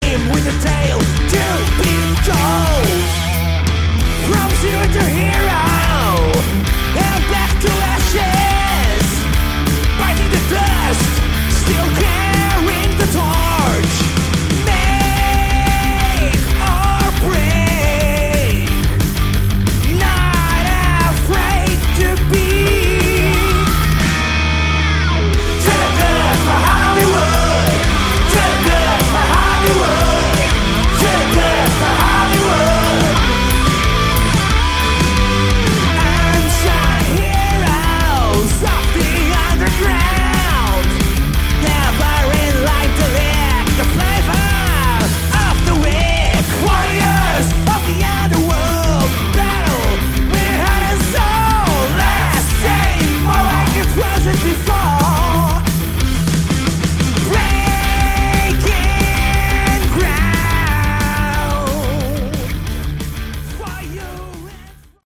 Category: Hard Rock
guitar, backing vocals
bass, backing vocals
drums, backing vocals